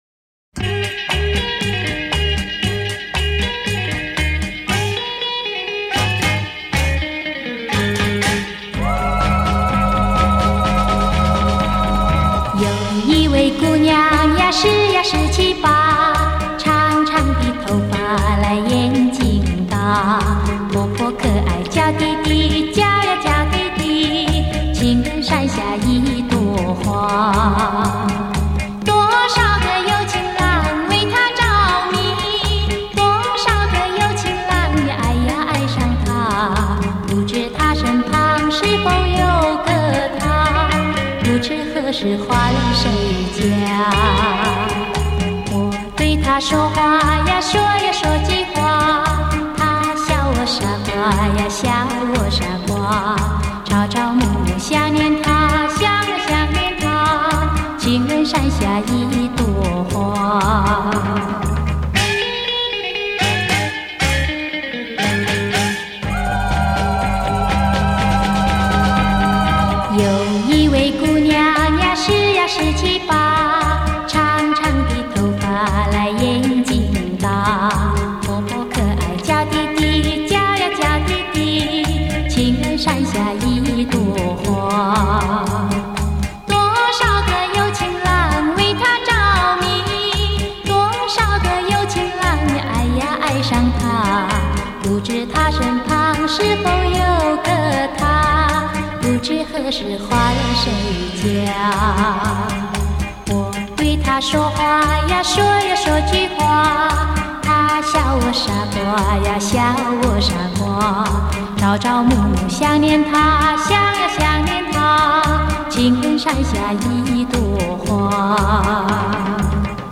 更有她在演唱歌曲时，发音吐字清晰、准确，